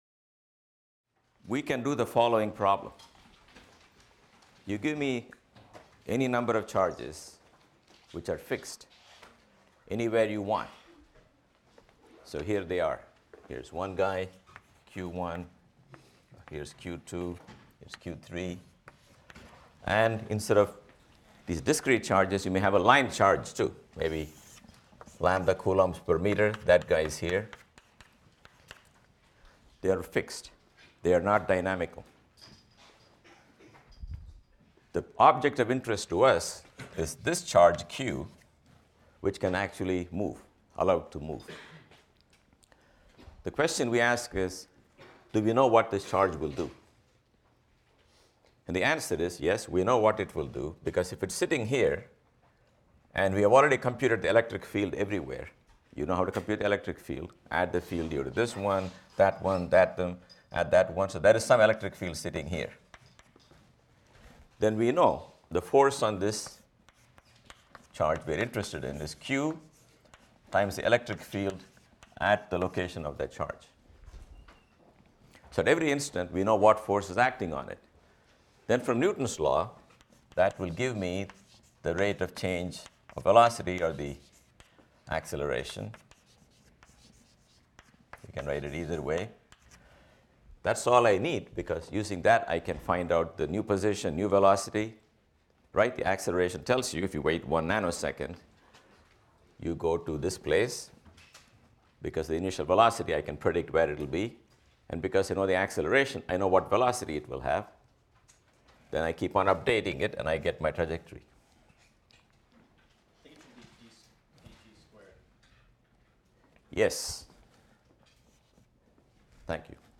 PHYS 201 - Lecture 5 - The Electric Potential and Conservation of Energy | Open Yale Courses